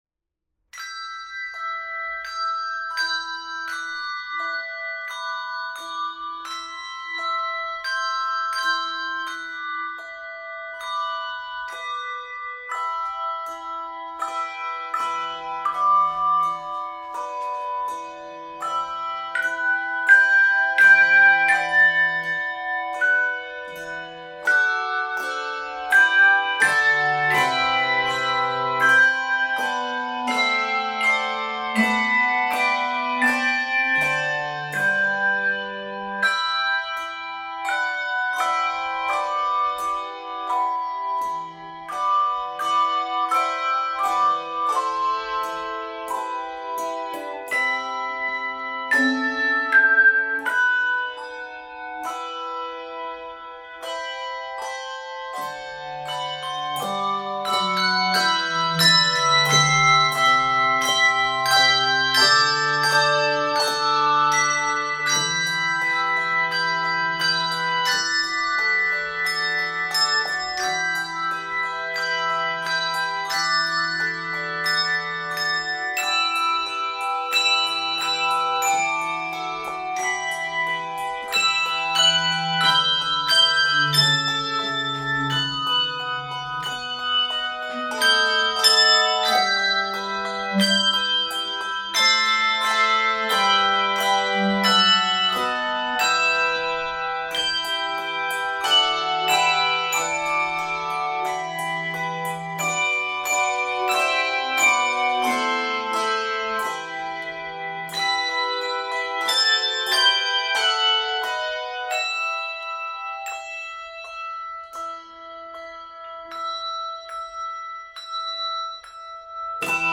Voicing: 3 Octave Handbells and Handchimes